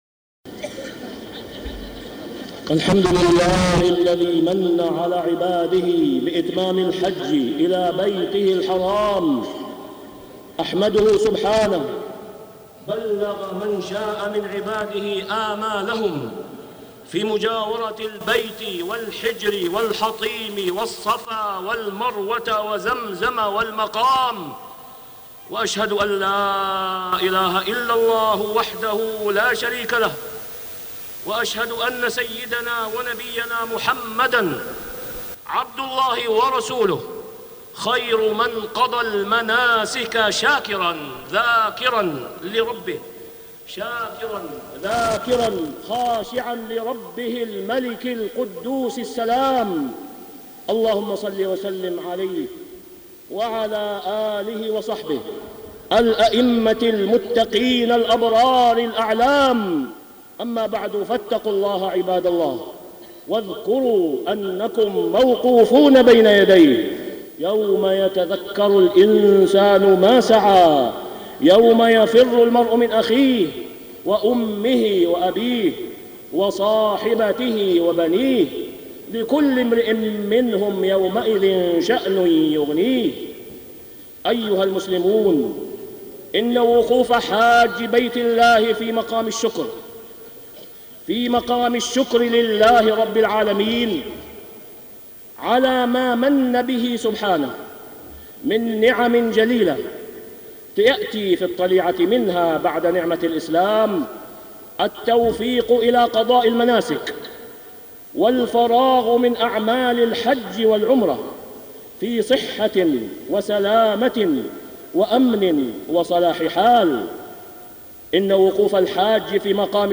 تاريخ النشر ١٦ ذو الحجة ١٤٢٧ هـ المكان: المسجد الحرام الشيخ: فضيلة الشيخ د. أسامة بن عبدالله خياط فضيلة الشيخ د. أسامة بن عبدالله خياط مقام الشكر بعد ركن الحج